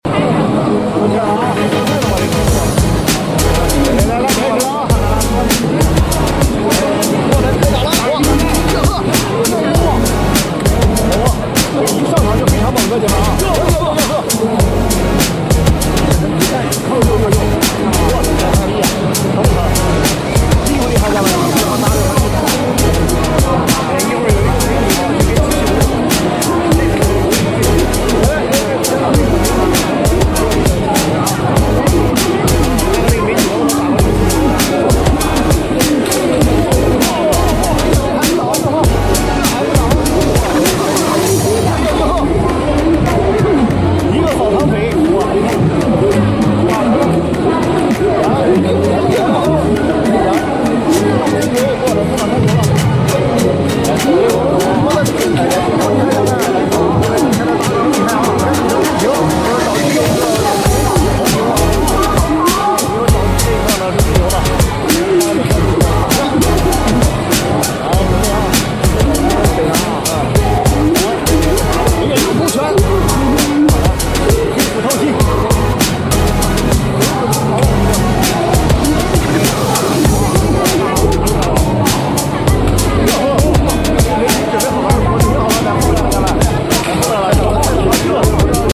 🥊🤖 Insane Robot Fight! Unitree sound effects free download